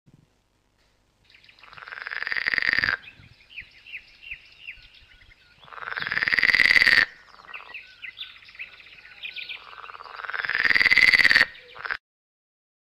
Rane verdi italiane
Canto-verso-rana-verde-italiana.mp3